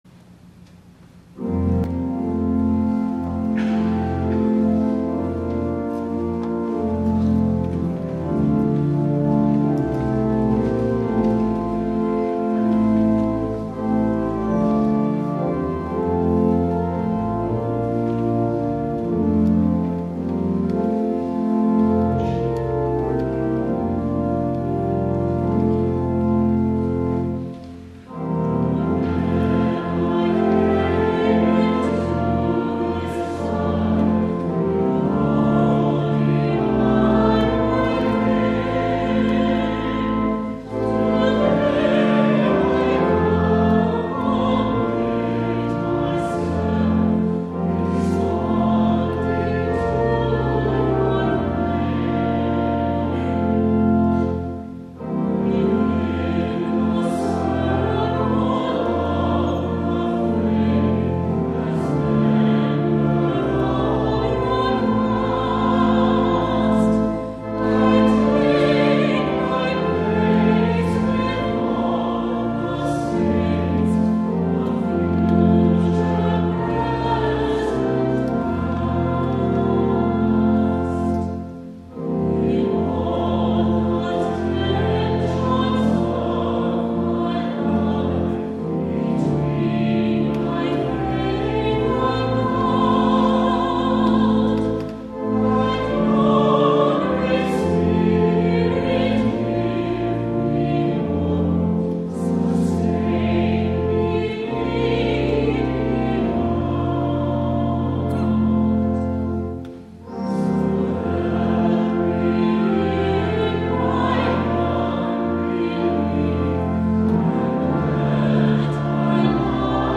7:30 P.M. WORSHIP
THE SOLO
soprano
cello